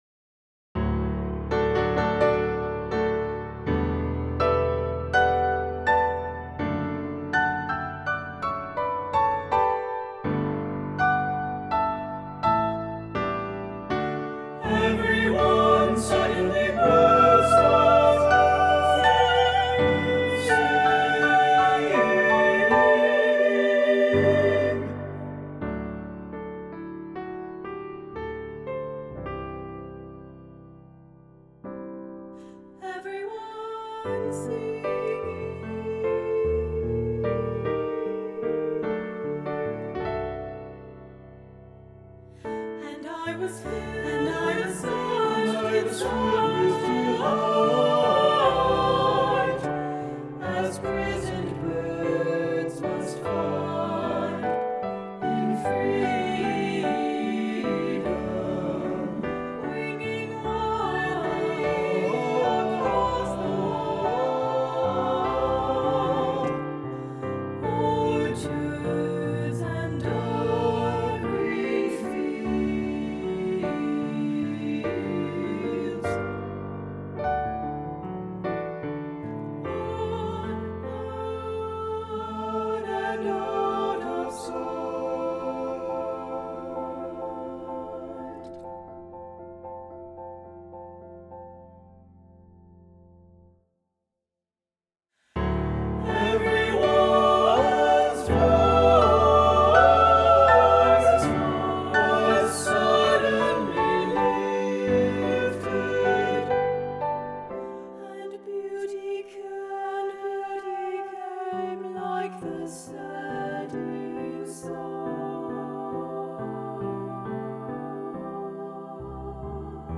3-part mixed choir, piano
partsong